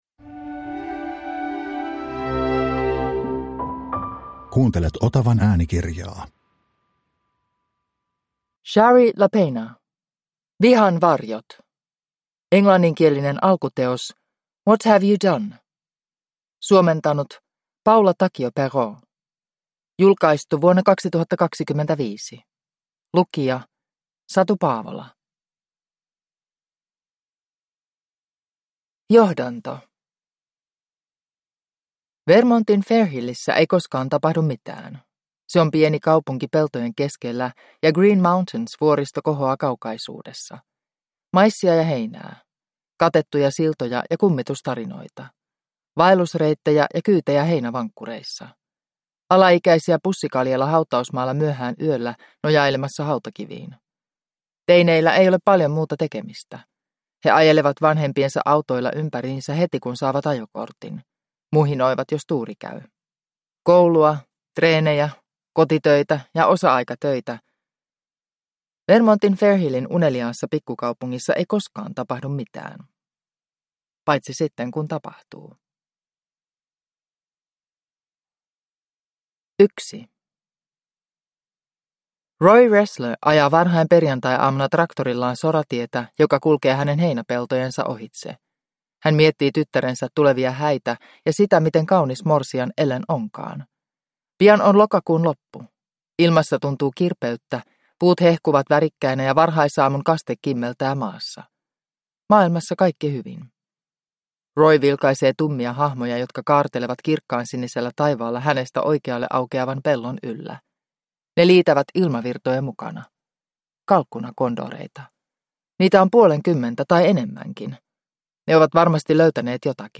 Vihan varjot (ljudbok) av Shari Lapena